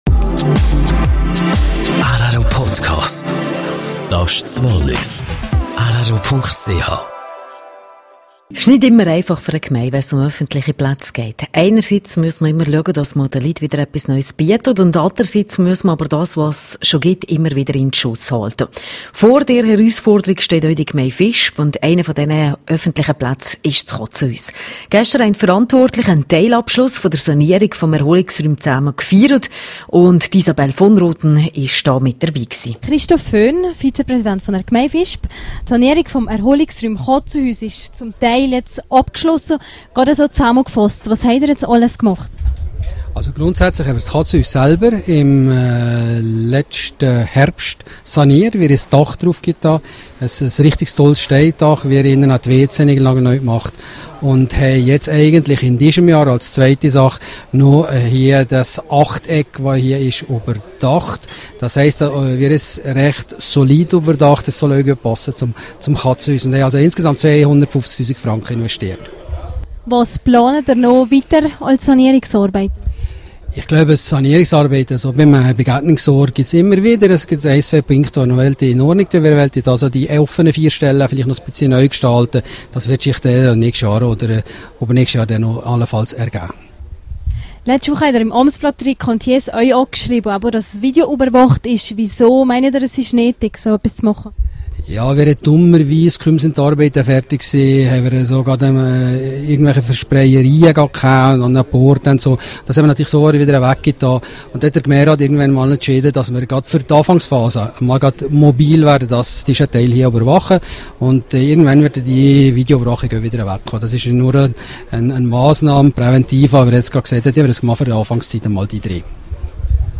Interview mit Christoph Föhn, Vizepräsident Gemeinde Visp, zum sanierten Erholungsraum Chatzuhüs.